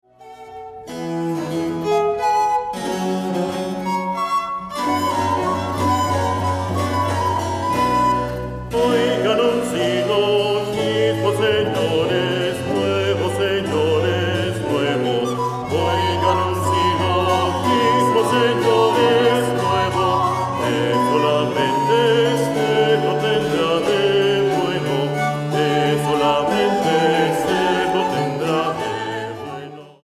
Versiones barroco americano